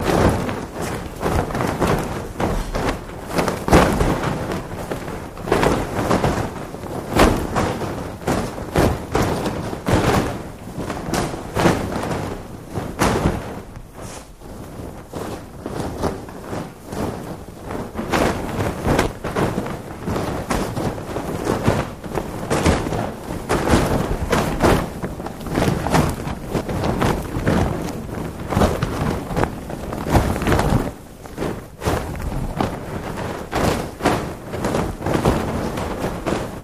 Parachute Being Rustled Up, Down